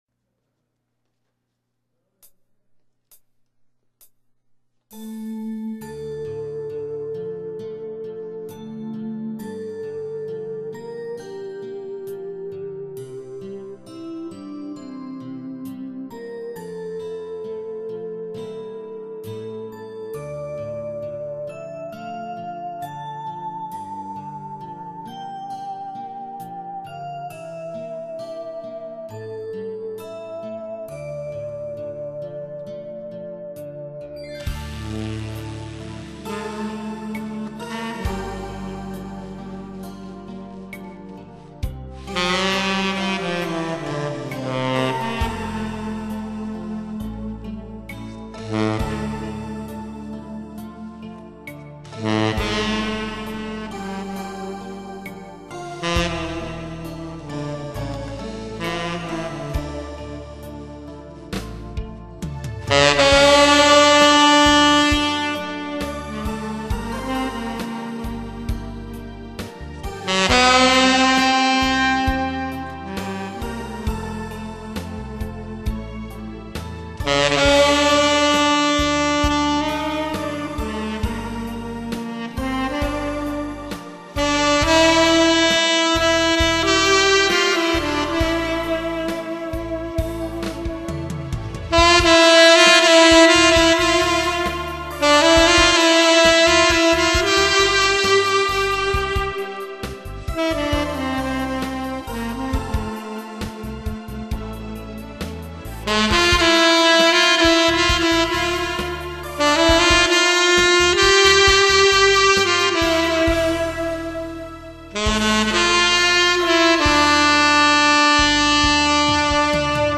먼저 알토로 연주해 올린게 있지만 이번에는 테너로 한번 해봤습니다.